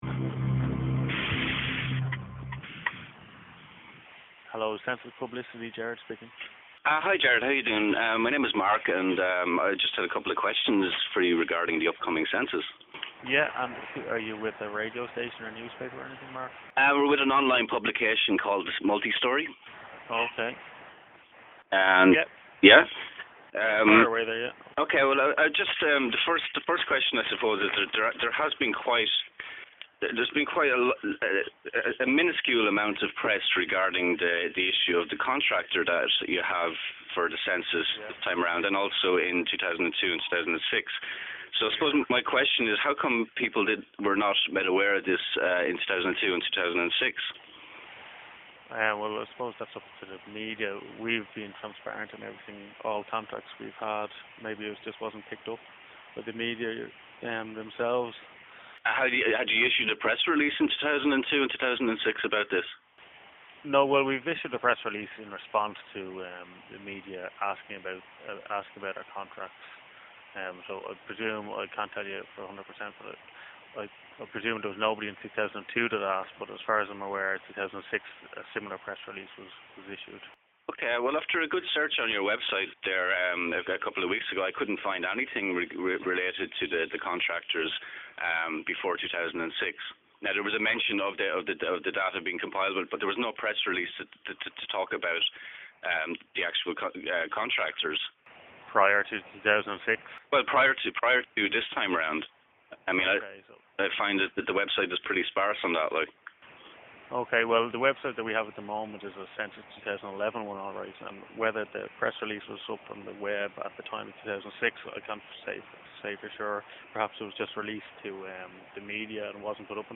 [inclusive] Interview with the CSO